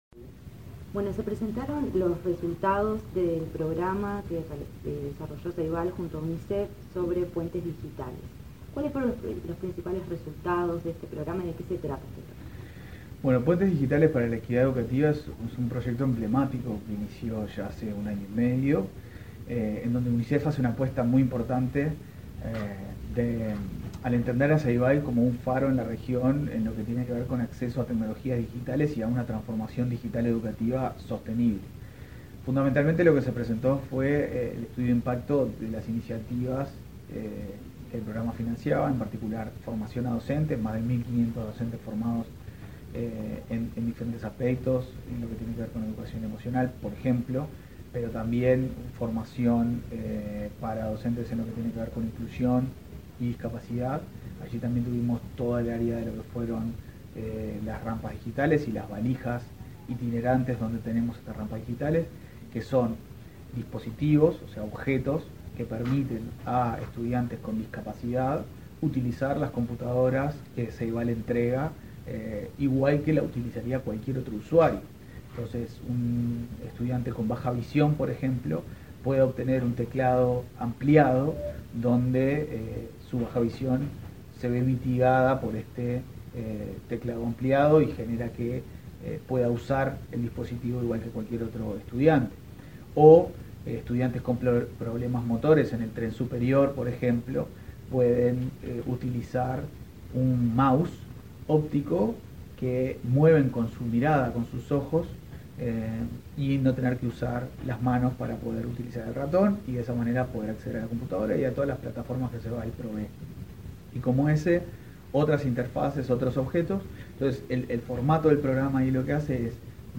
Entrevista al presidente de Ceibal, Leandro Folgar